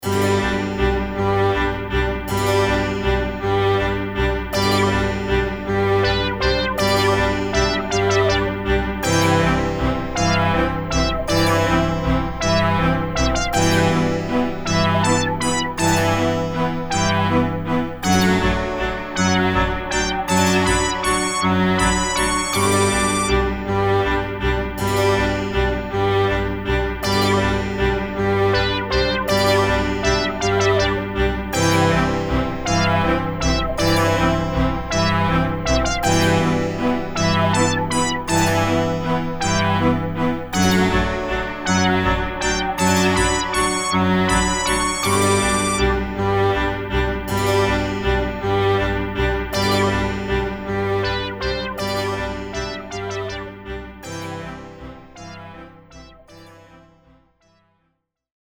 game music
style emulation